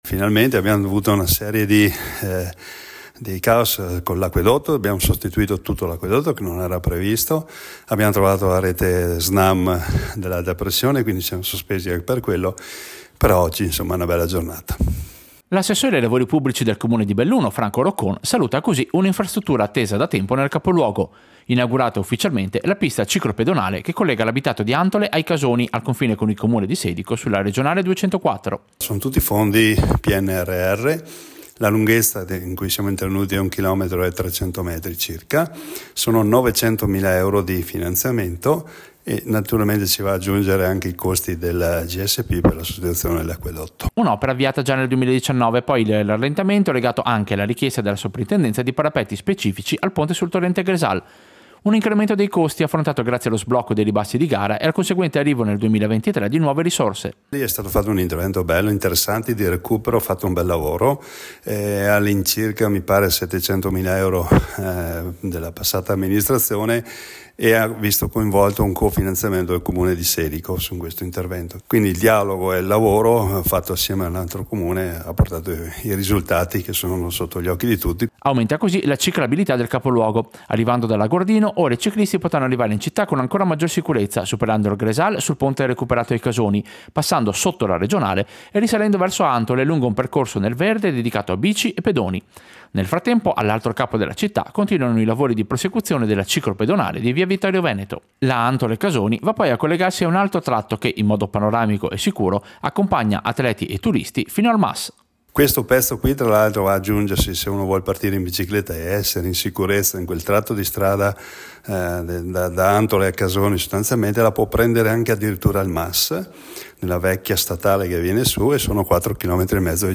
Servizio-Inaugurazione-ciclabile-Antole-Casoni.mp3